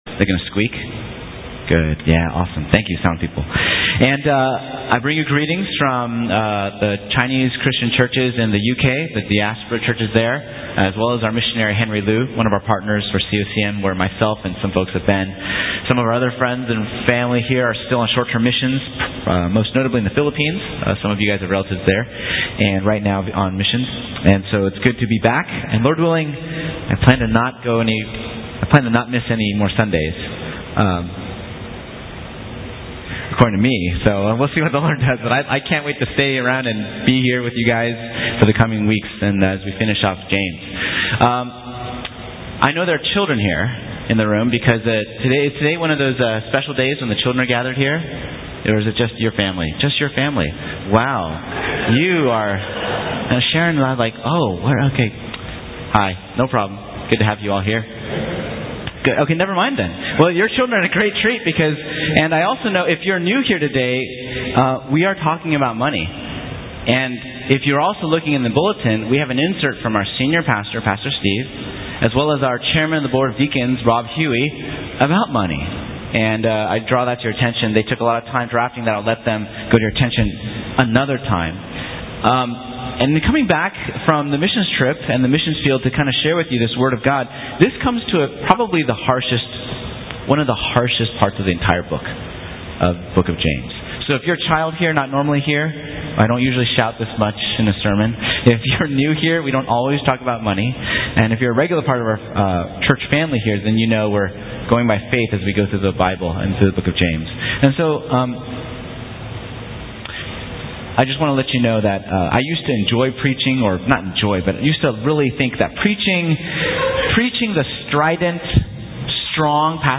Tag: Recent Sermons - Page 101 of 178 | Boston Chinese Evangelical Church